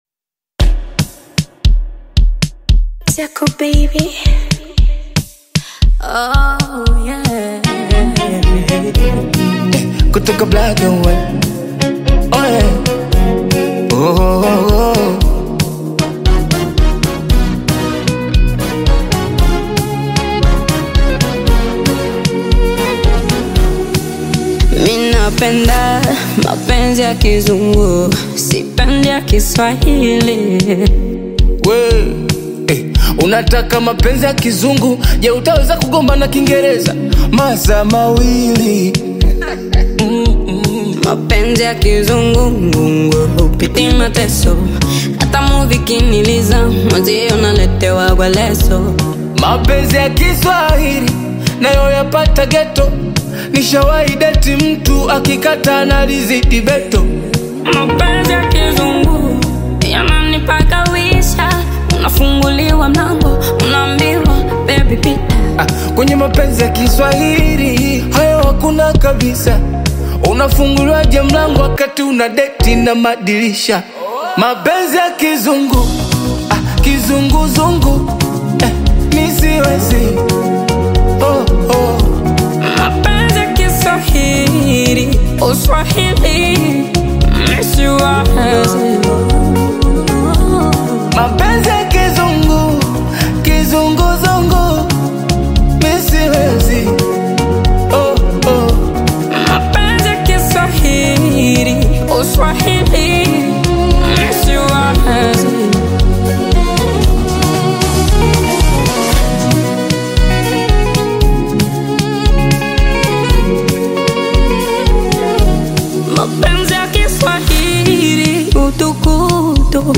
featuring the soulful vocals